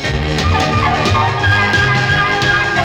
This is a vinyl transfer, so it’s not going to win any Grammys for sound quality.